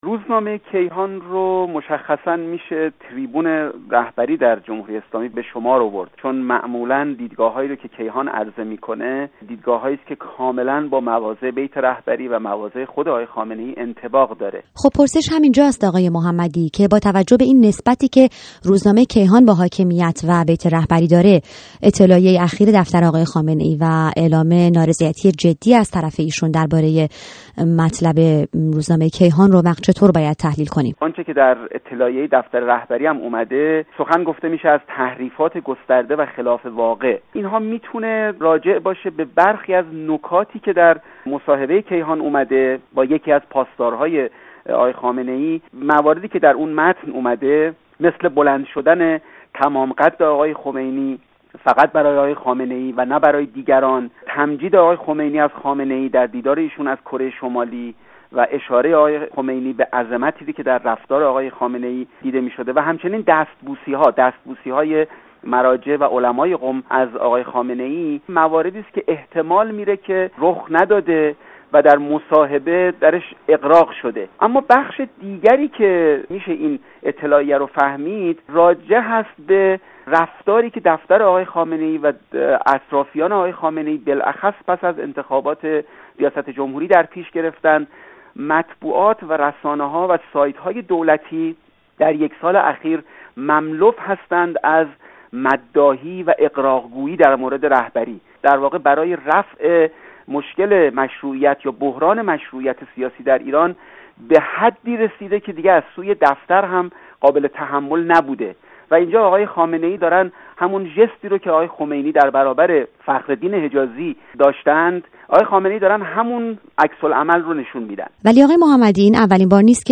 با دو تحلیلگر سیاسی در باره اعتراض دفتر آیت الله خامنه ای به روزنامه کیهان